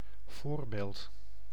Ääntäminen
Vaihtoehtoiset kirjoitusmuodot enstance (harvinainen) enstaunce (vanhentunut) instaunce Synonyymit case in point Ääntäminen US : IPA : [ˈɪn.stənts] UK : IPA : /ˈɪn.stəns/ Tuntematon aksentti: IPA : /ˈɪnt.stənts/